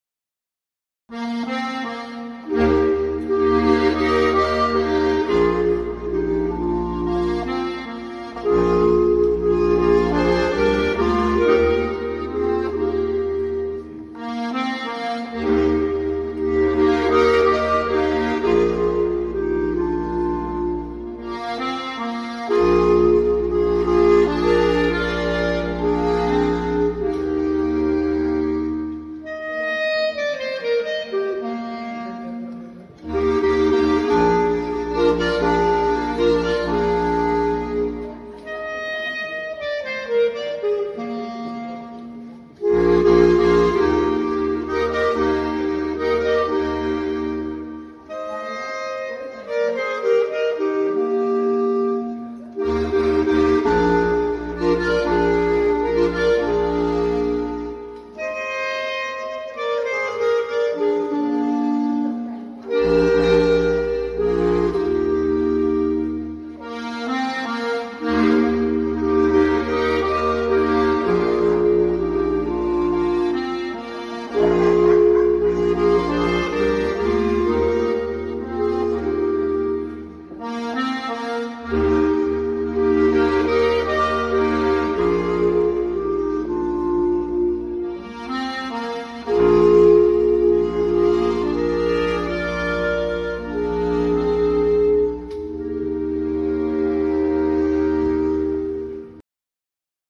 Es ging auf die Lenzbaueralm in Pichl/Kainisch (Salzkammergut).
Natürlich dürfte auch musizieren nicht fehlen. Immerhin spielten wir ja für unsere Frauen, aber auch für die anwesenden Wanderer.
Jodler gespielt.
lenzbaueralm-riefesberg-jodler-a.mp3